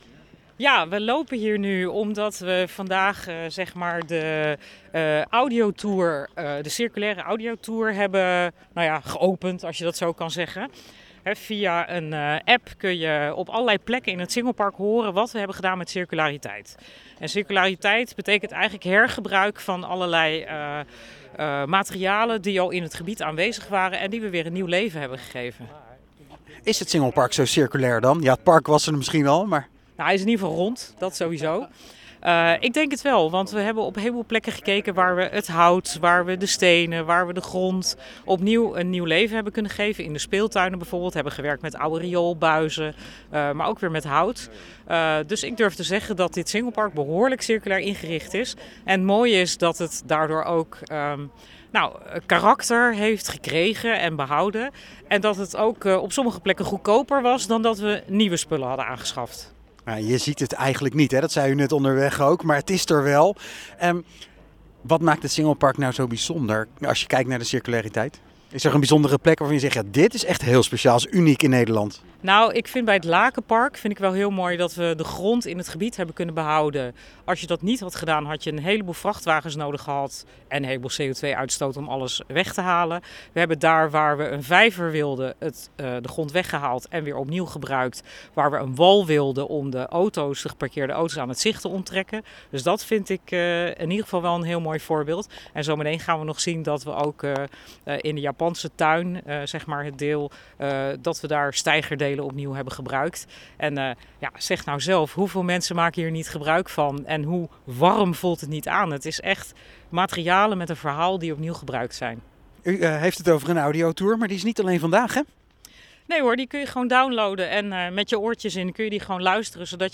in gesprek met wethouder Yvonne van Delft over de audiotour en het Singelpark